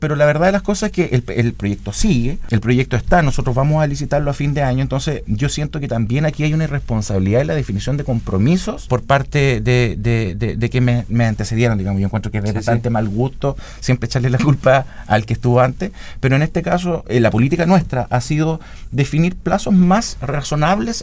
En conversación con Radio Sago, el Seremi de Obras Públicas de la región de Los Lagos, Daniel Olhabé, se refirió al futuro del Puente Cancura, luego de pasar cuatro años de su desplome.